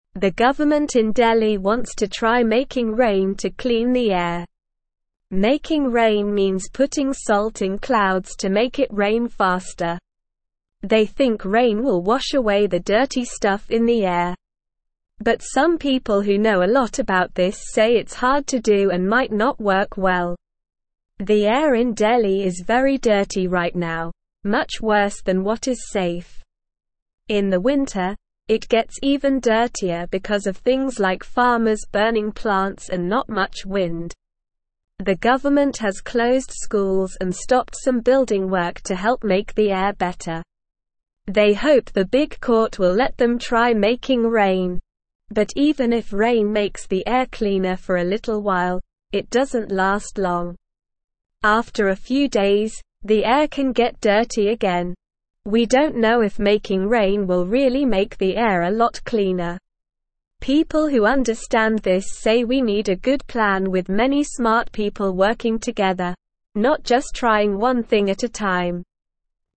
Slow
English-Newsroom-Lower-Intermediate-SLOW-Reading-Making-Rain-to-Clean-Delhis-Dirty-Air.mp3